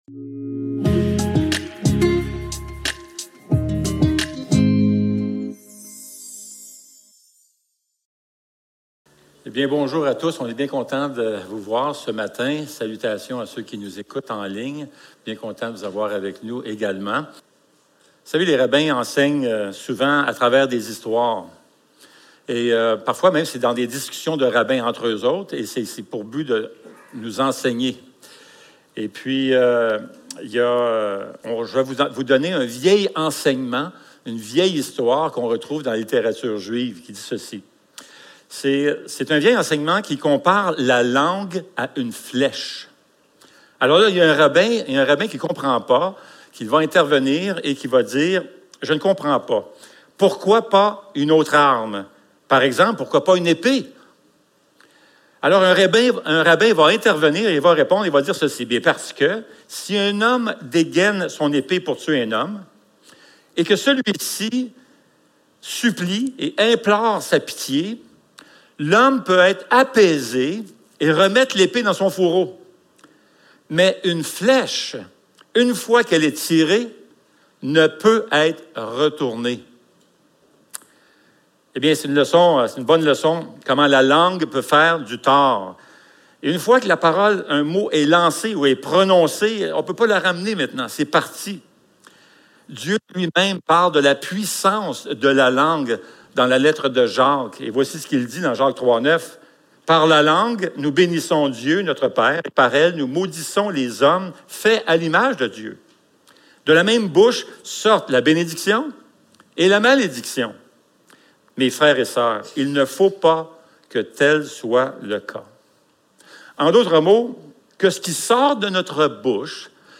Éphésiens 4. 29-32 Service Type: Célébration dimanche matin Éveil à la Grâce #4 Ces mots qui nous blessent!